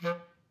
Clarinet
DCClar_stac_F2_v2_rr1_sum.wav